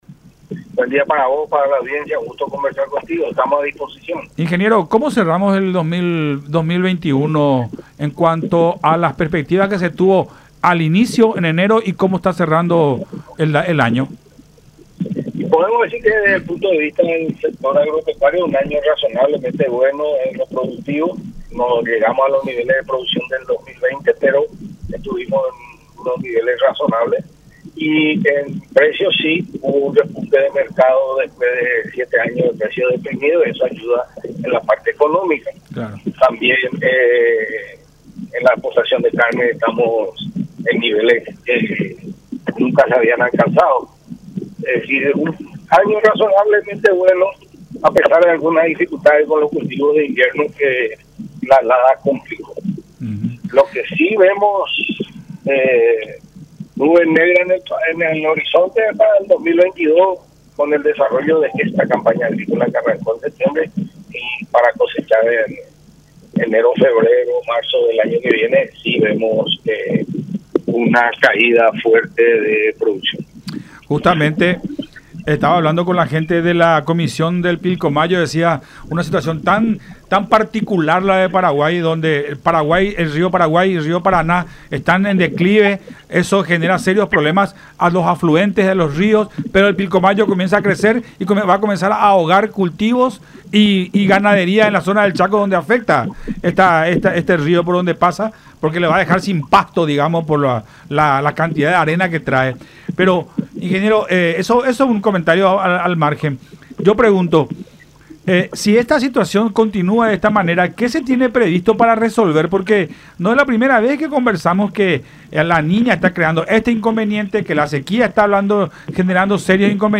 en diálogo con Enfoque 800 a través de La Unión.